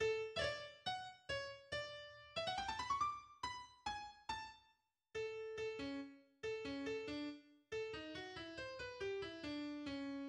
Introduction du Menuetto:
Il est accompagné de fanfares aux cuivres et à la timbale, qui accentuent son caractère majestueux et presque pompeux.